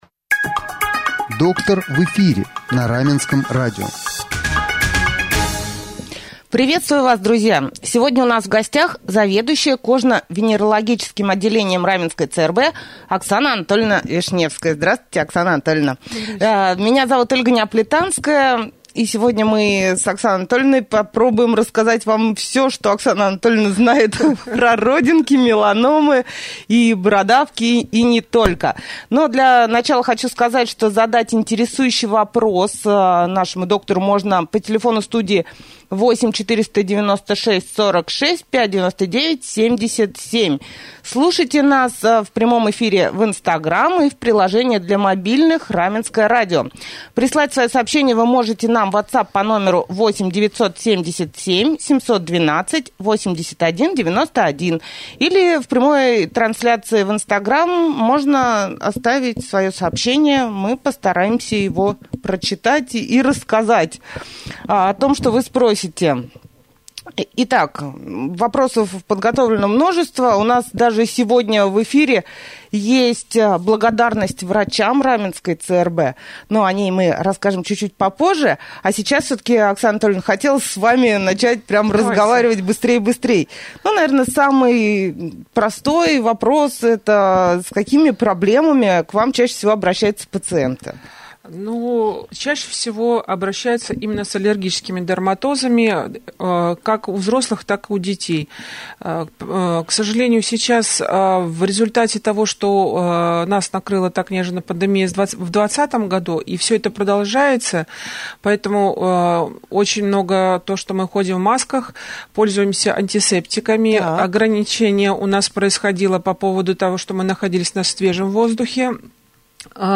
На эти и другие вопросы узнаете ответы из прямого эфира Раменского радио.
prjamoj-jefir-kvd-1.mp3